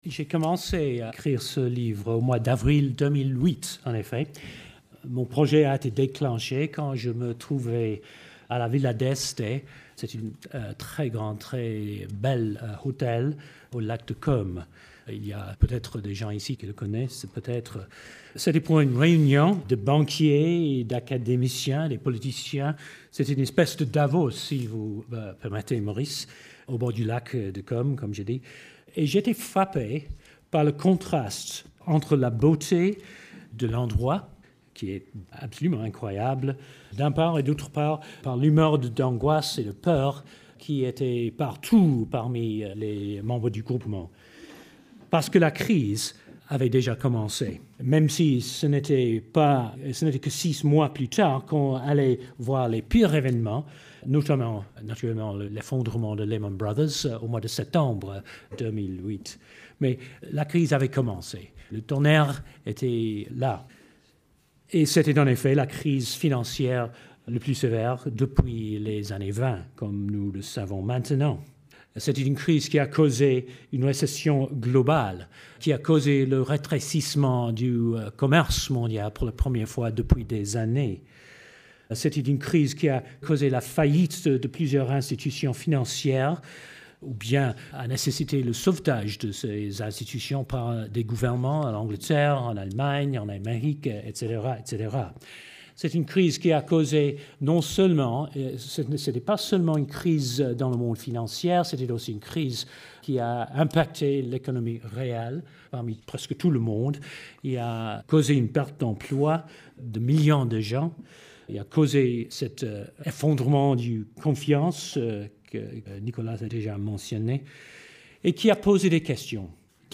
Le Collège des Bernardins a invité Stephen Green, actuel Ministre du commerce et de l’investissement dans le gouvernement de David Cameron, et Maurice Lévy, P.-D.G. de Publicis Groupe, pour évoquer la dimension morale de l’économie aujourd’hui. Canal Académie vous propose la retransmission de cet échange d’idées.